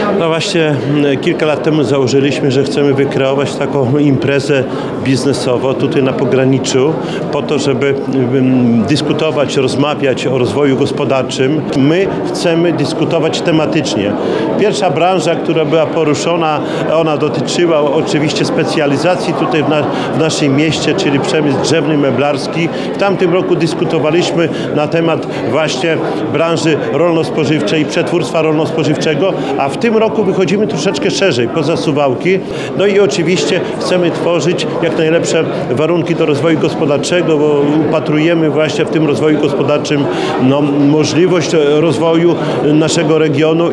Mówi Czesław Renkiewicz, prezydent Suwałk.